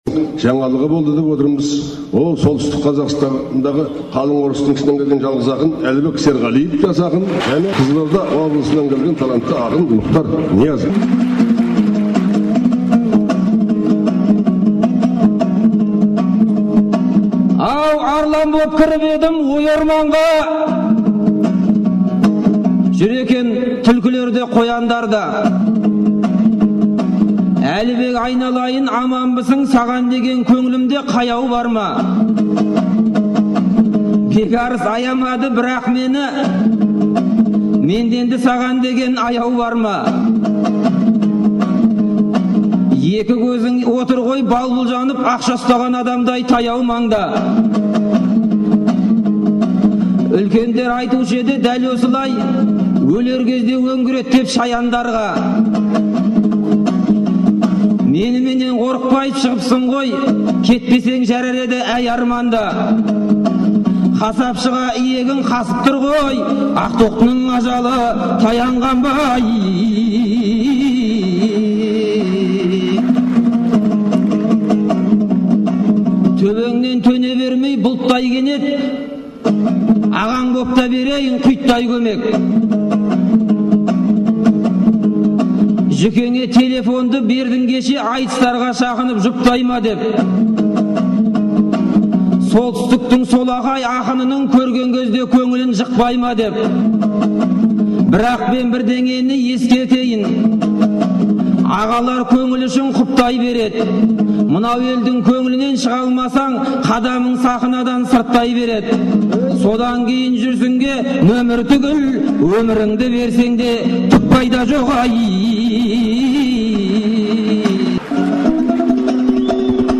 Астанада 2010 жылы желтоқсанда өткен «Тәуелсіздік тағылымы» айтысының үшінгі күні
жыр сайысы